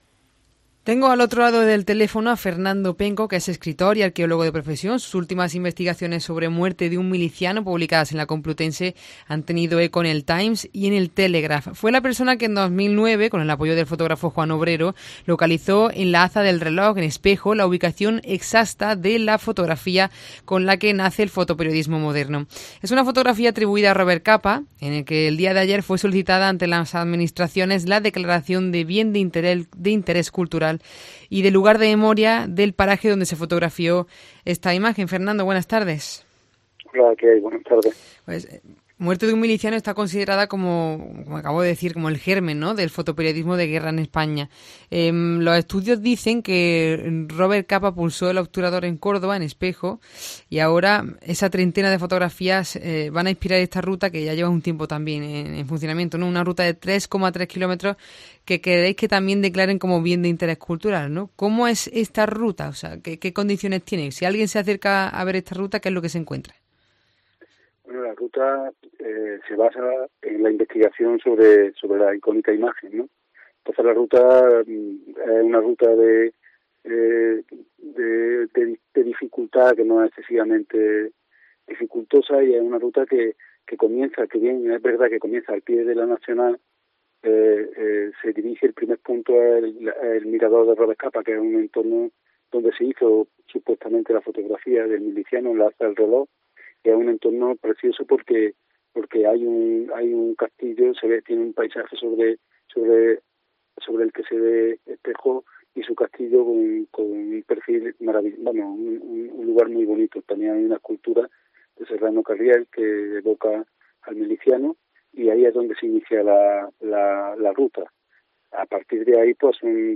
COPE conversa con uno de los arqueólogos investigadores que situaron en Córdoba esa imagen